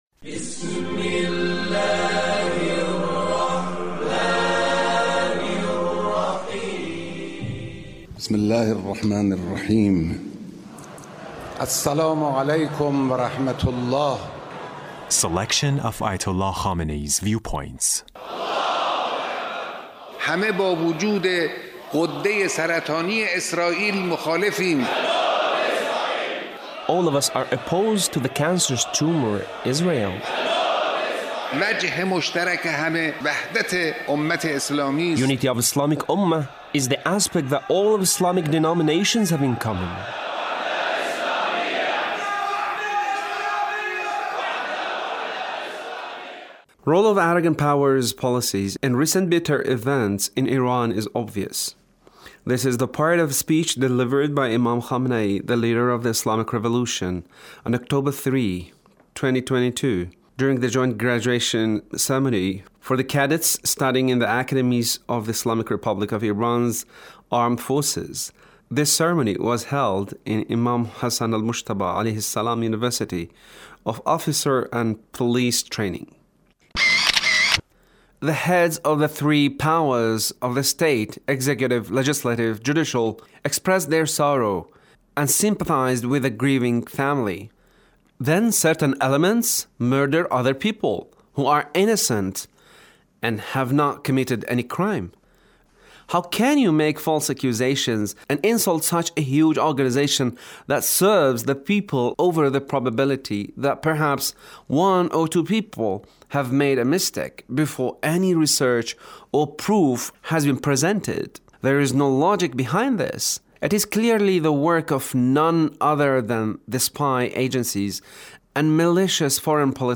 Leader's Speech on 13th of Aban